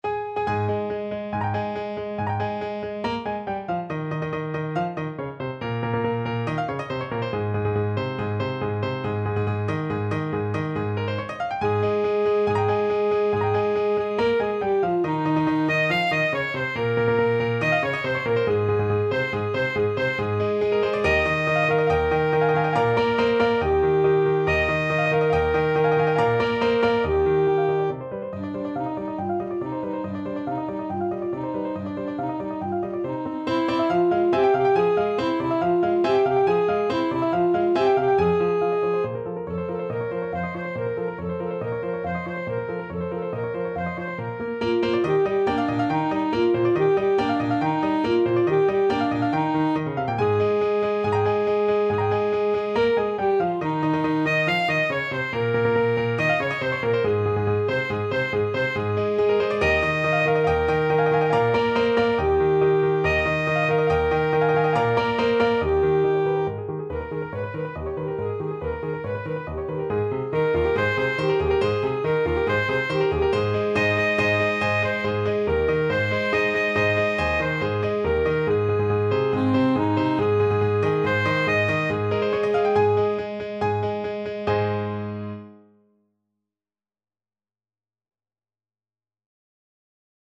Alto Saxophone
~ = 140 Allegro vivace (View more music marked Allegro)
2/4 (View more 2/4 Music)
Classical (View more Classical Saxophone Music)